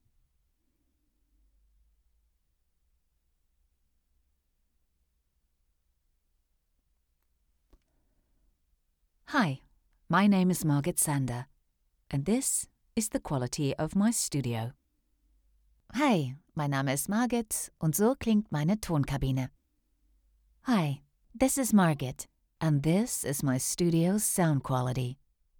Female
Approachable, Assured, Authoritative, Bright, Character, Confident, Conversational, Cool, Corporate, Energetic, Engaging, Friendly, Natural, Posh, Reassuring, Smooth, Soft, Upbeat, Versatile, Warm
My voice is versatile from warm, friendly, emotional, professional, edgy, modern, fun, enthusiastic and dynamic.
Microphone: Austrian Audio OC18